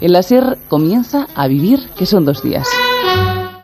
Salutació inicial
Entreteniment